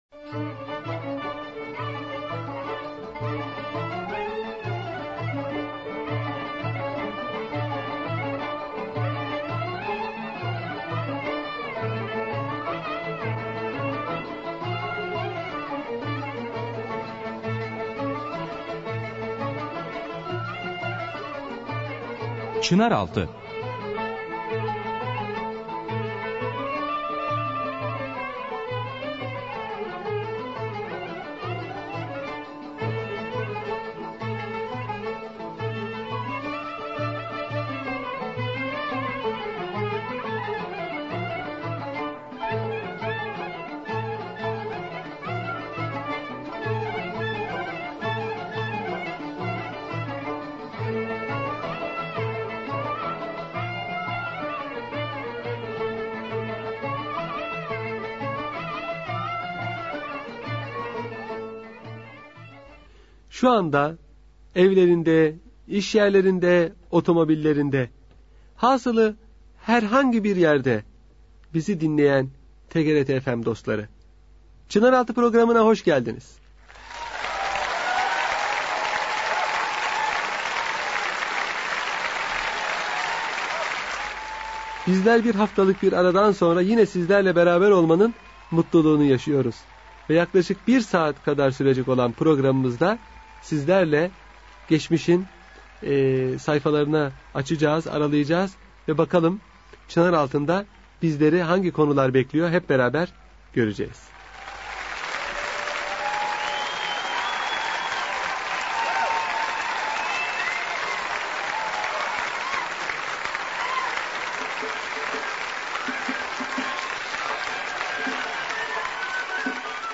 Radyo Programi - Türklerin İslamiyetten Önceki Dinleri